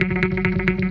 4 Harsh Realm Bass Click.wav